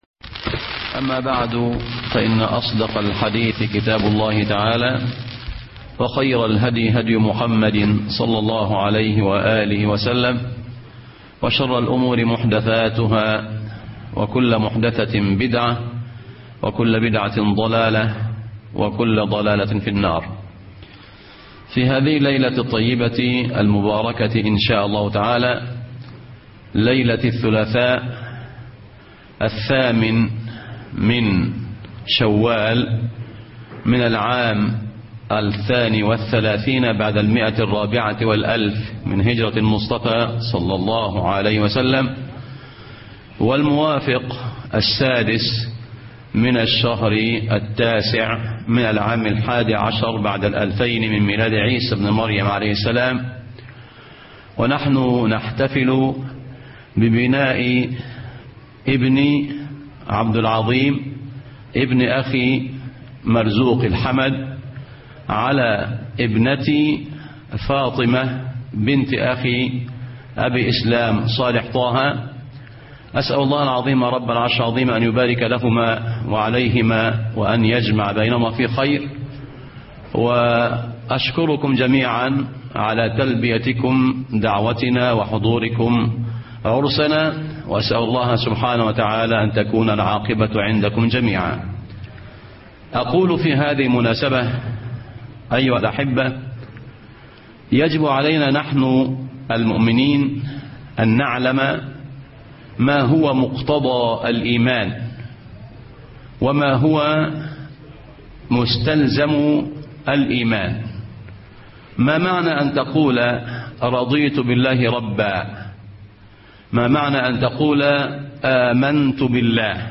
محاضرات خارج مصر